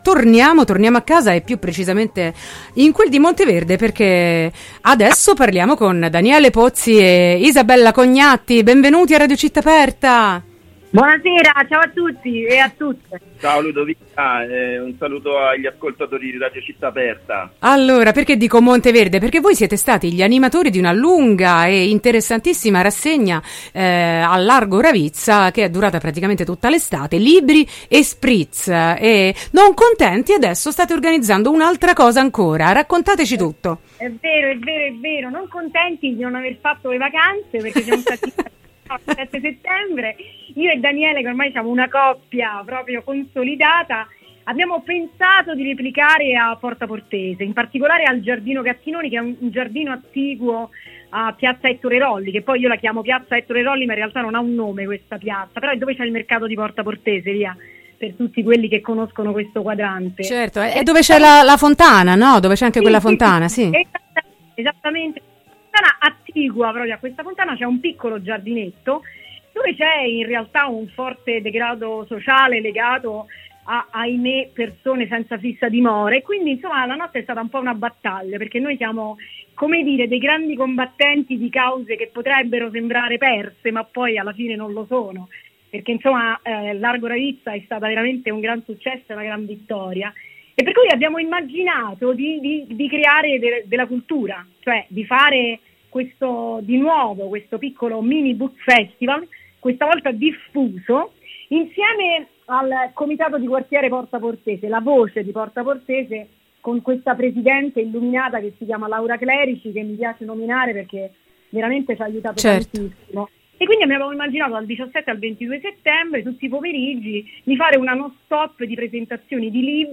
intervista-libriespritz-16-9-22.mp3